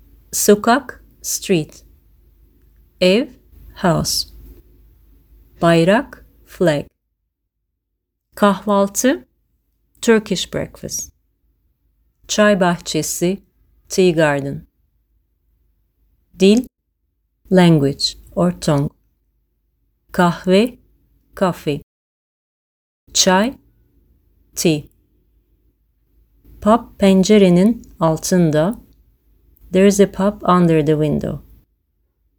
To help avoid confusion, I have italicised the Irish words and phrases, and I’ve also included some Turkish audio clips so you can hear what the language sounds like!
Turkish Vocabulary
Turkish-Vocab.mp3